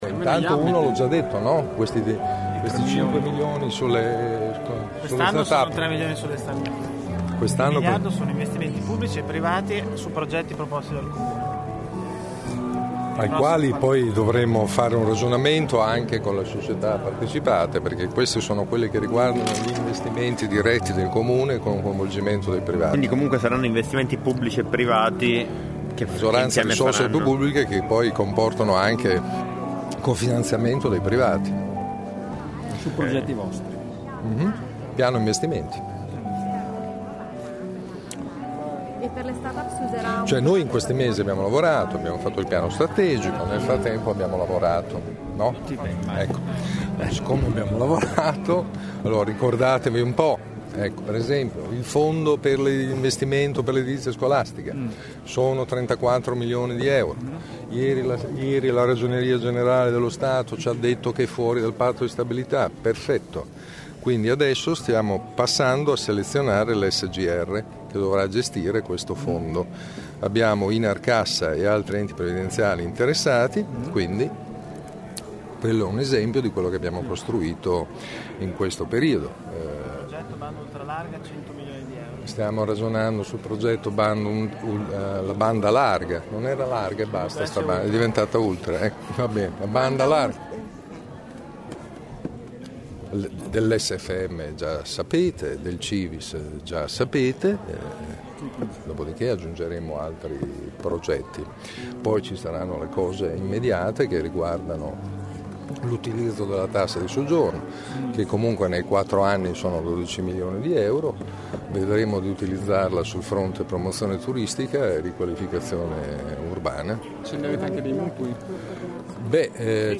In attesa di conoscere dal governo su quali trasferimenti Imu potrà contare per chiudere il bilancio, a margine dell’evento, il sindaco elenca i progetti in attesa di partire, che in alcuni casi prevedono un co-finanziamento privato.
Ascolta il sindaco Merola e il coordinatore di giunta Matteo Lepore